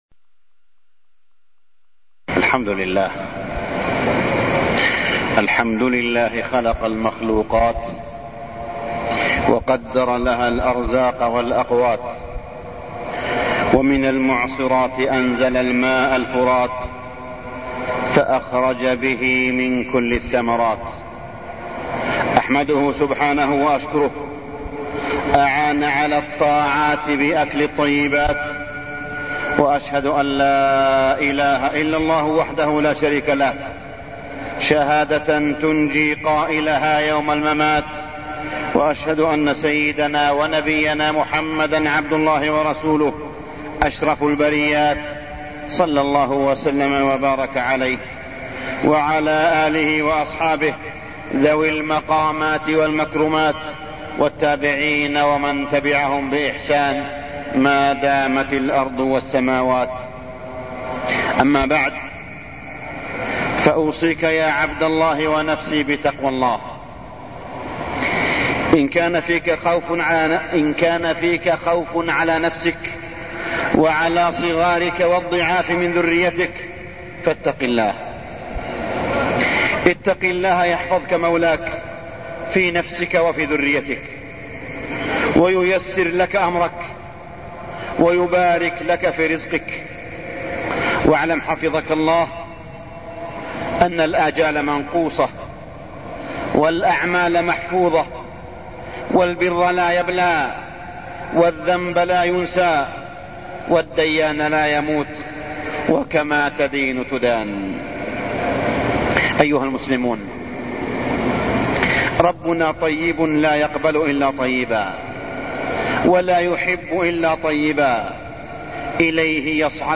تاريخ النشر ٢٨ جمادى الآخرة ١٤٢٠ هـ المكان: المسجد الحرام الشيخ: معالي الشيخ أ.د. صالح بن عبدالله بن حميد معالي الشيخ أ.د. صالح بن عبدالله بن حميد التخفف من المطعوم والمشروب